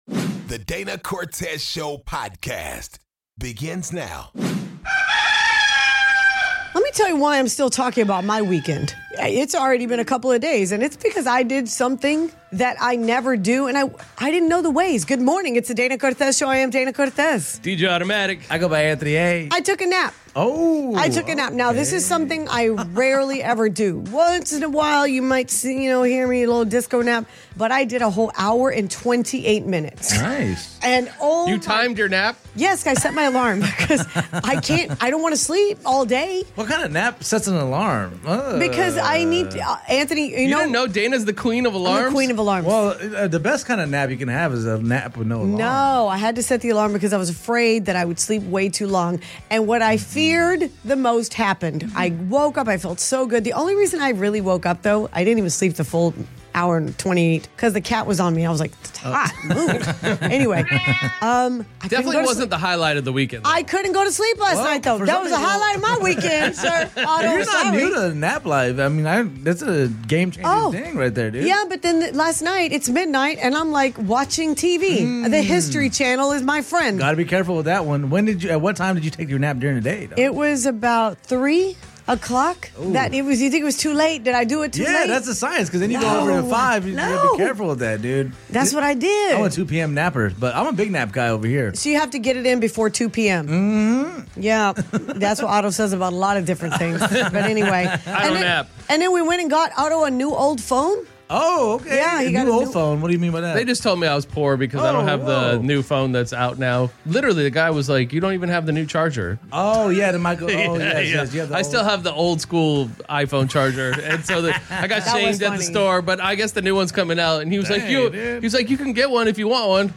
some listeners call in to tell their stories